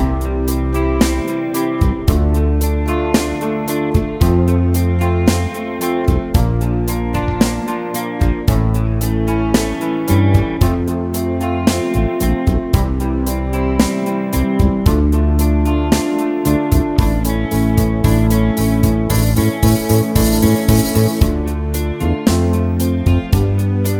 Full Version With No Saxophone Pop (1980s) 4:46 Buy £1.50